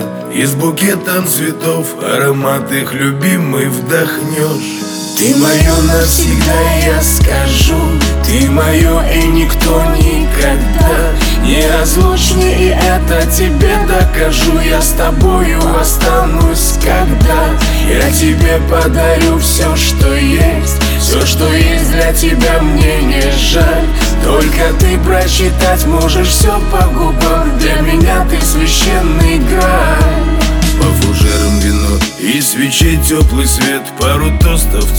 Жанр: Шансон / Русские